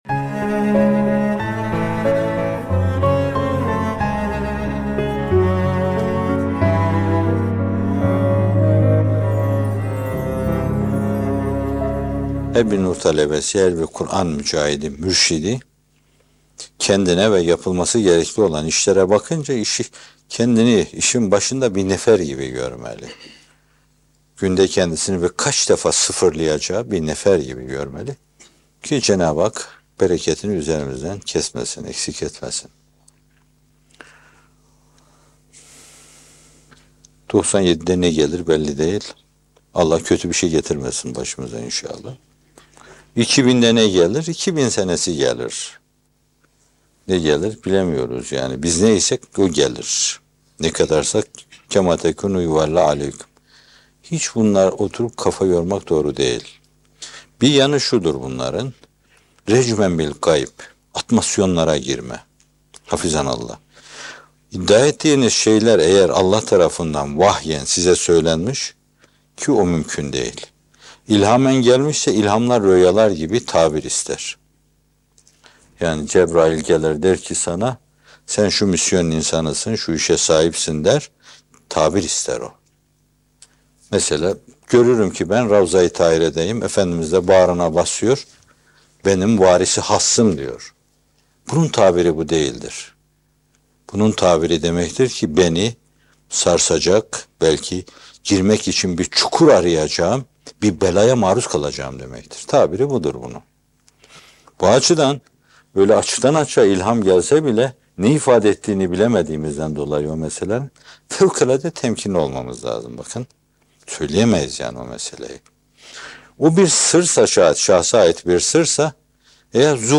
Geleceğe Dair Kehanet ve İhlâs Mesleği - Fethullah Gülen Hocaefendi'nin Sohbetleri